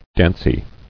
[danc·y]